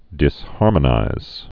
(dĭs-härmə-nīz)